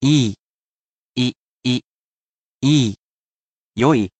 ii / yoi